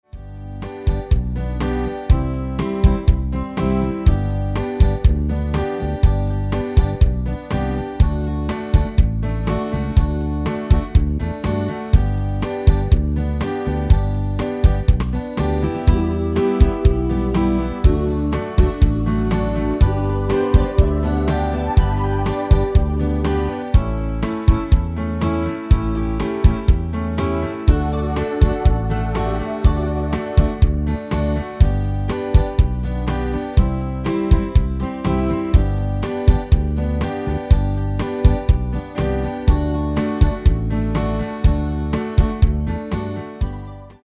Demo/Koop midifile
Genre: Nederlands amusement / volks
- GM = General Midi level 1
- Géén vocal harmony tracks